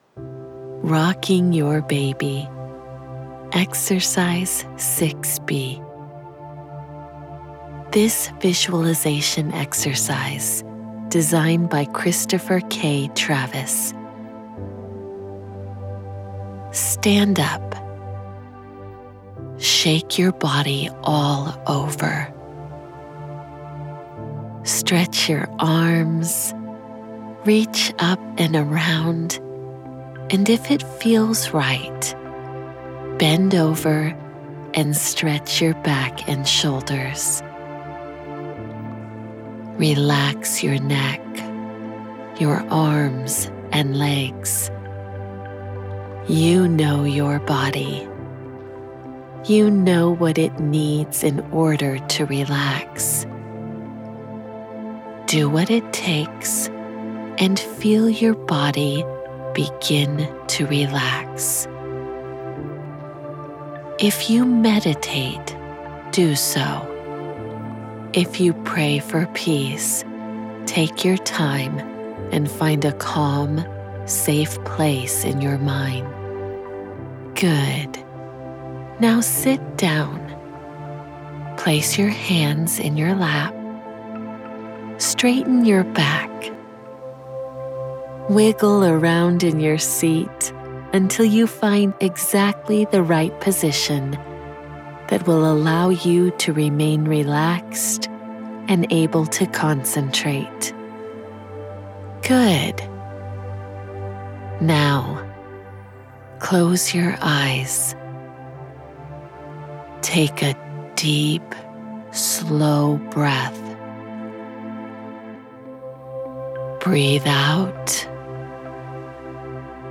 Rocking the Baby: Guided Visualization